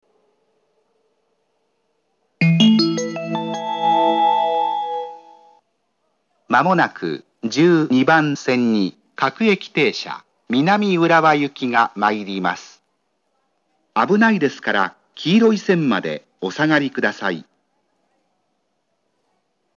１２番線接近放送
発車メロディー（春ＮｅｗＶｅｒ）
旧型のＡＴＯＳ放送でした。
※発車メロディーには、駅員放送が被っております。